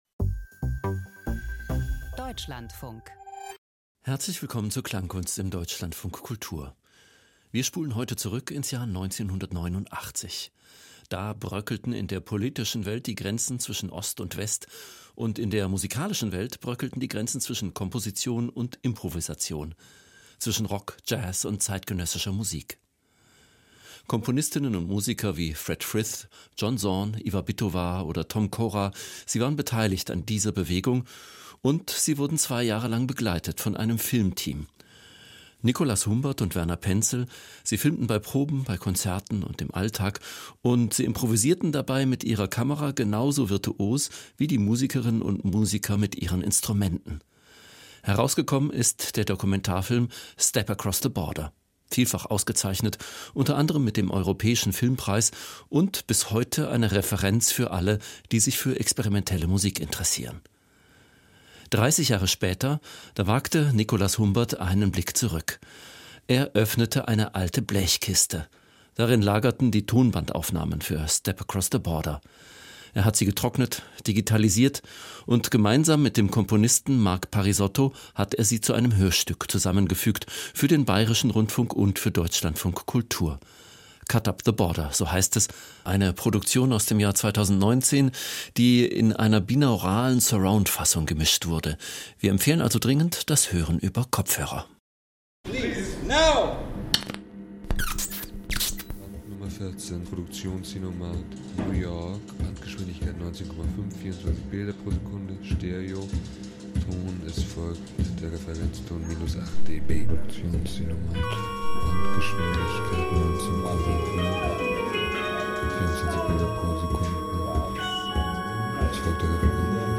Soundcollage aus einem Dokumentarfilm-Klassiker über den Gitarristen Fred Frith und die Kunst der Improvisation.